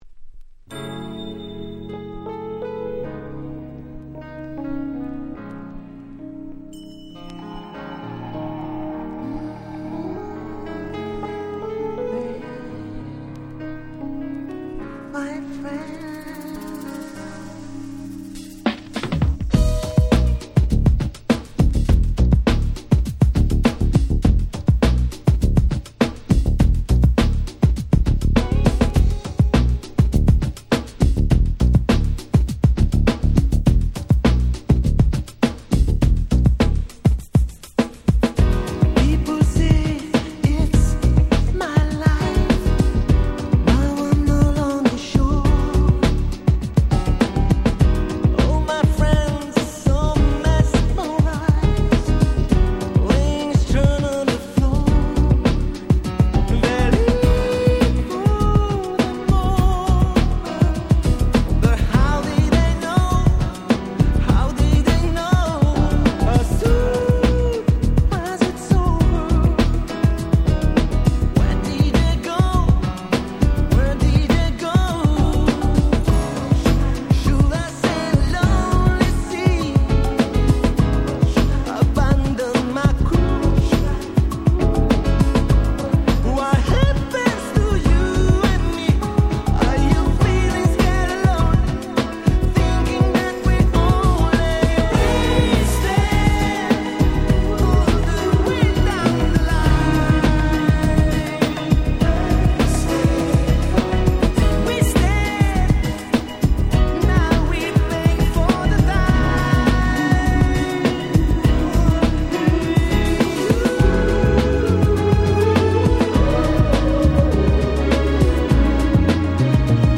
92' Very Nice UK R&B / UK Street Soul Album !!
もう教科書通りの爽やかで素敵なUK Soulしか入っておりません。